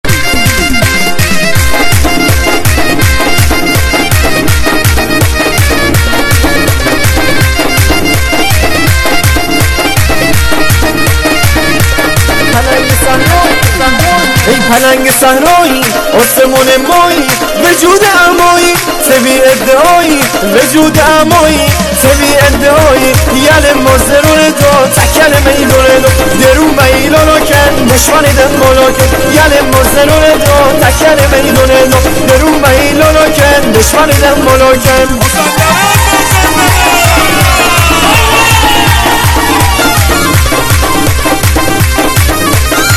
مازندرانی شوتی برای سیستم ماشین